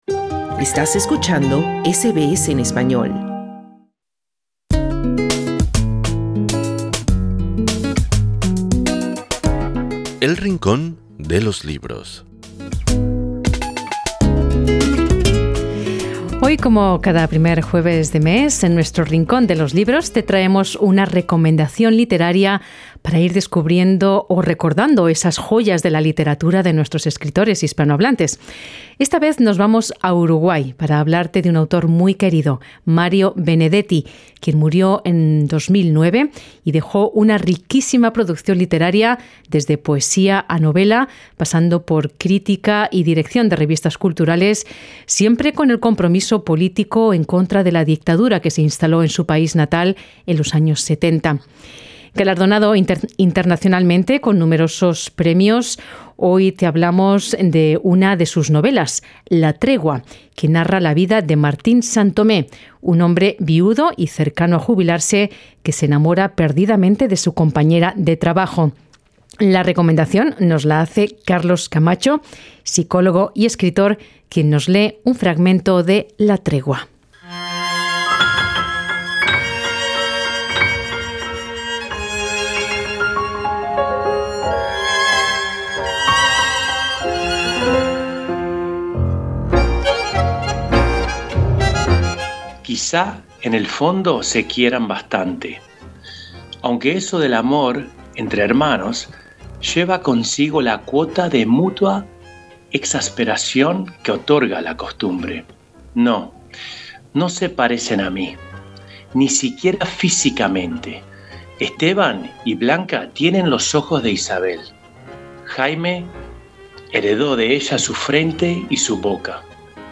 Escucha la entrevista completa presionando la imagen principal.